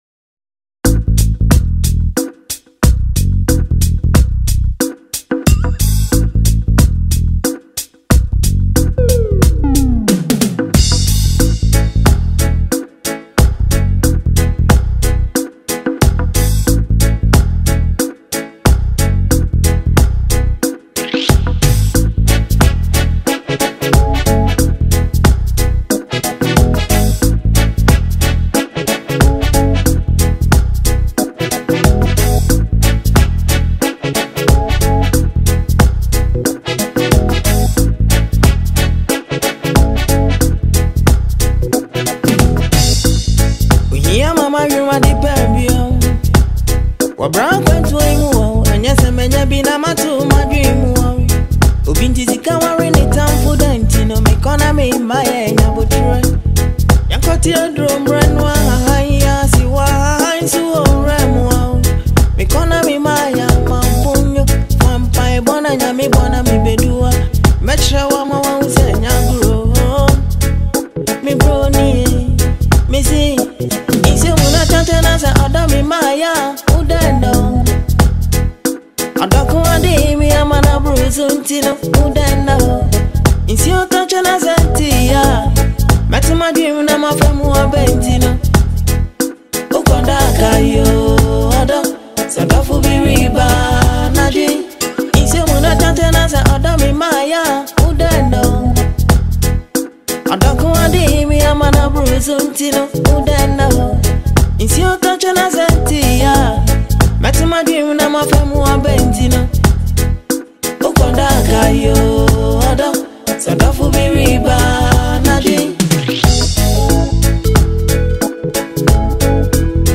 Legendary Ghanaian highlife icon
In this emotional highlife tune